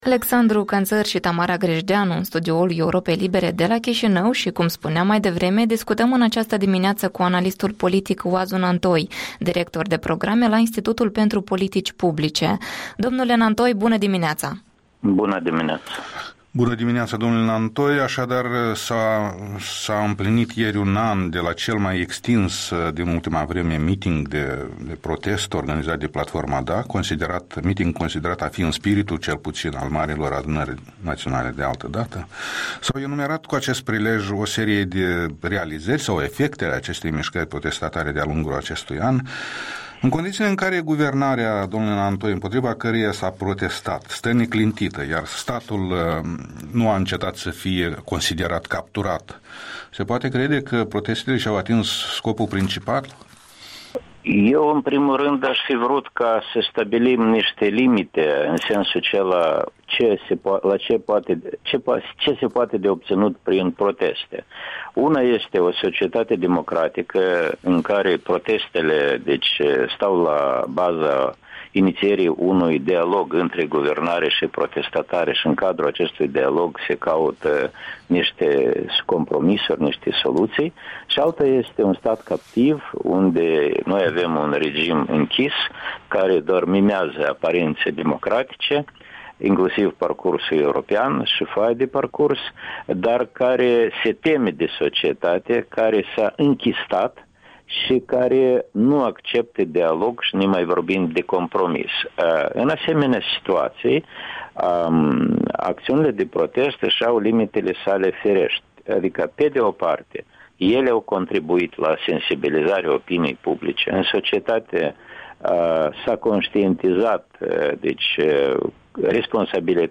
Interviul dimineții cu analistul politic Oazu Nantoi